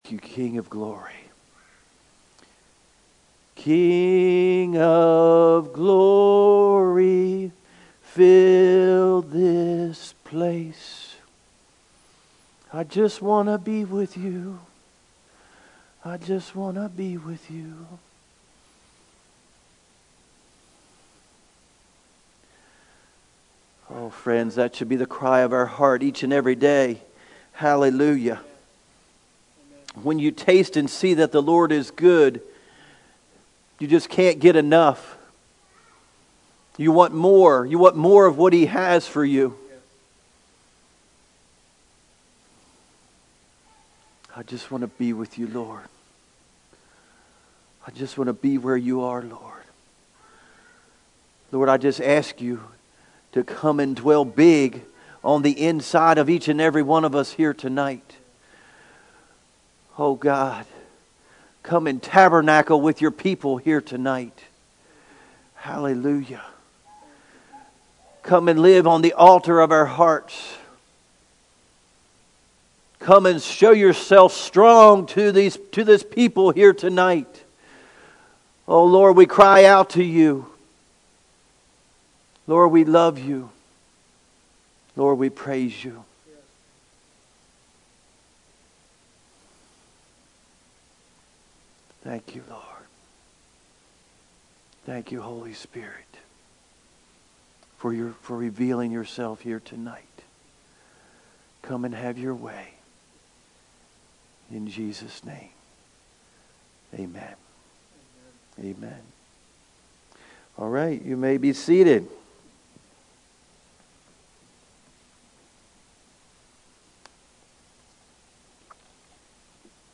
Sermons | New Freedom Church